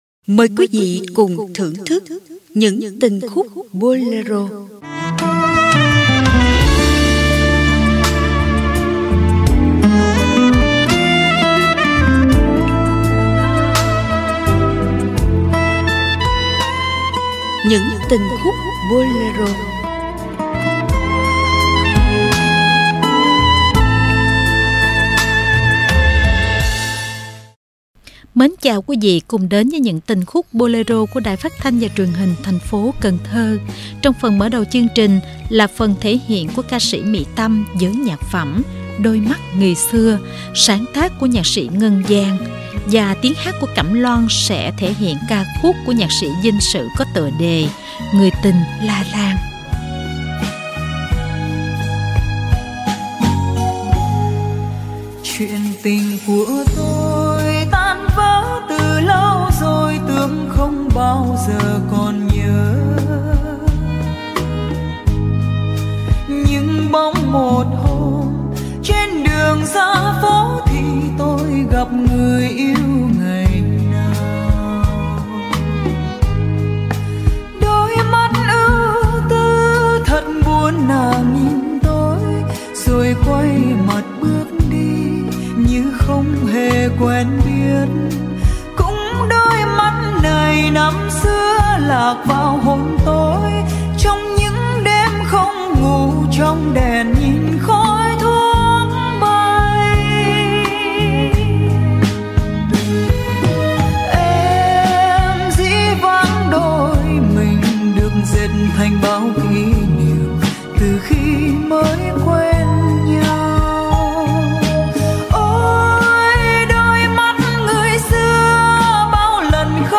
Ca nhạc Radio